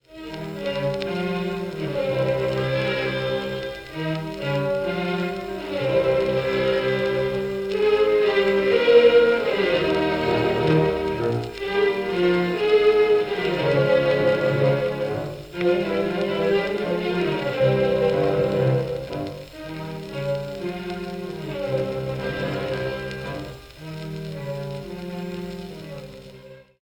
Reproducción de un disco de pizarra de 78 revoluciones
tocadiscos
Sonidos: Música